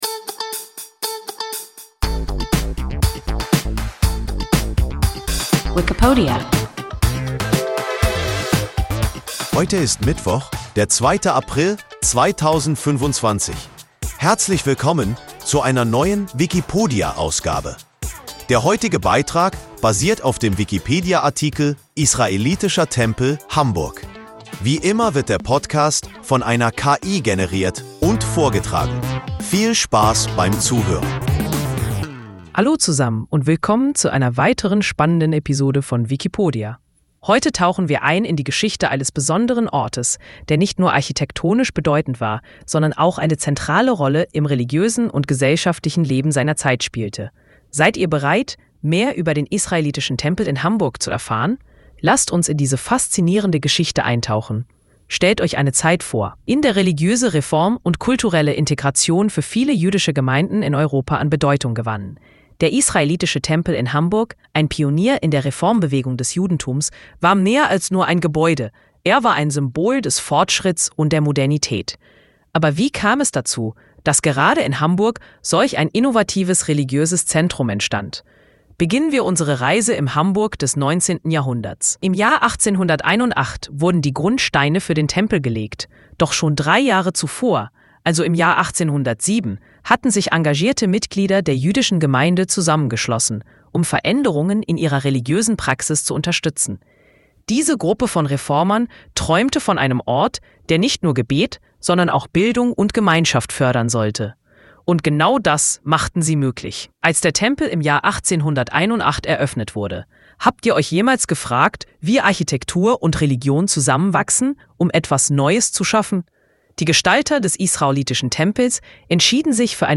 Israelitischer Tempel (Hamburg) – WIKIPODIA – ein KI Podcast